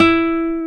Index of /90_sSampleCDs/Roland L-CD701/GTR_Nylon String/GTR_Classical